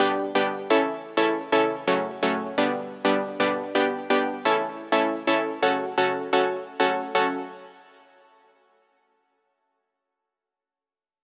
VDE1 128BPM Full Effect Chords Root G.wav